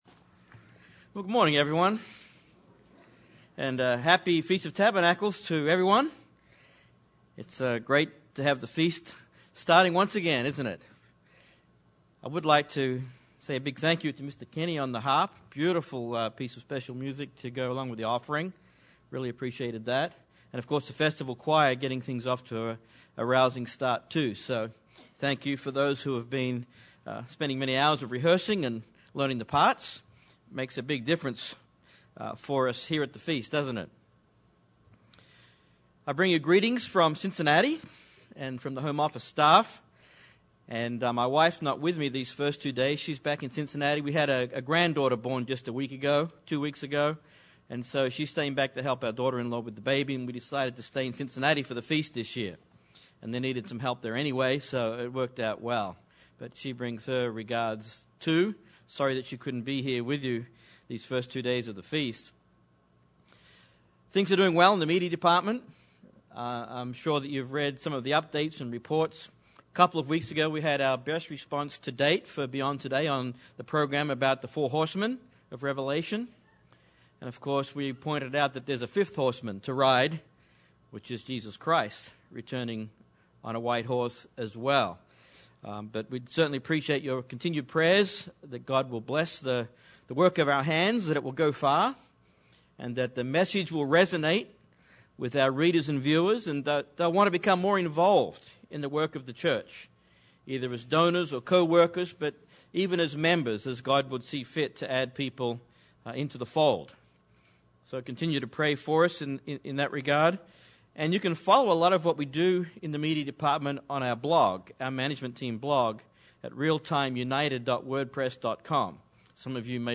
This sermon was given at the Branson, Missouri 2013 Feast site.